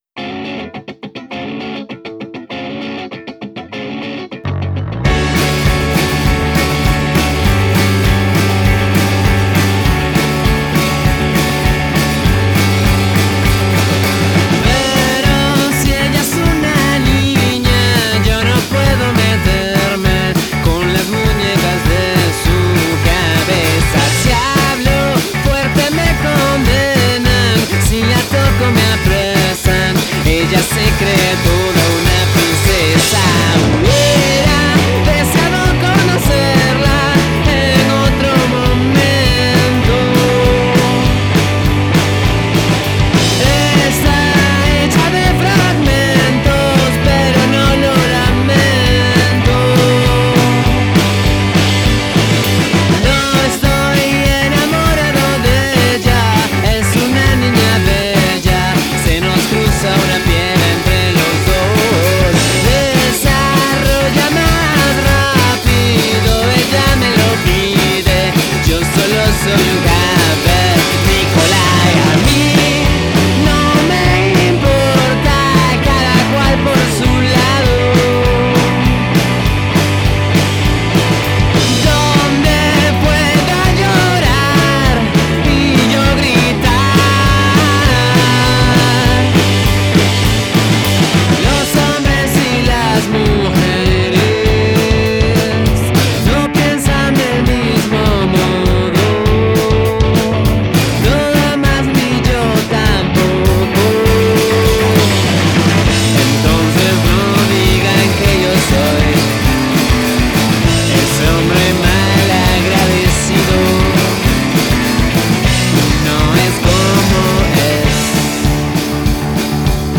Ingeniero de grabación